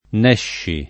n$šši] (antiq. nescio [n$ššo]) s. m. — solo nella locuz. tosc. e lett. fare il n. (raro far da n.)